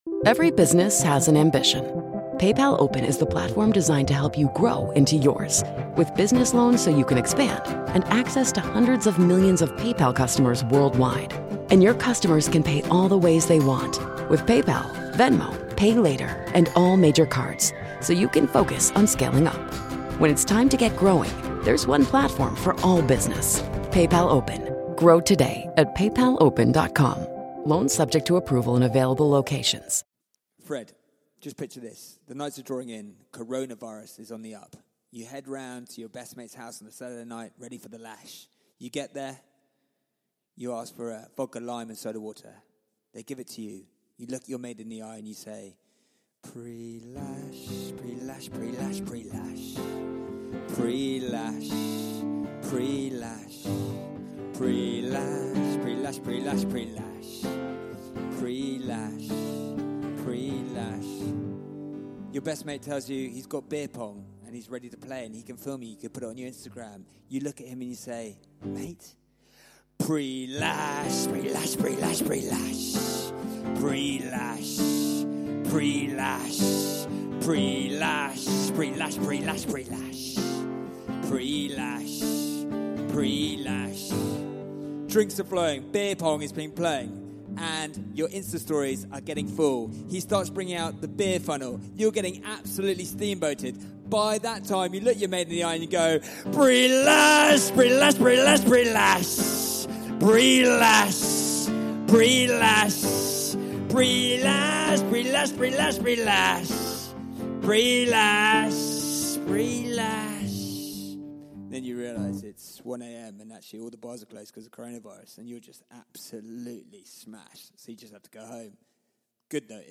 Today we return to interview one of the most respected and adored rugby players to have ever donned the Scottish Thistle in Mr Rory Lawson.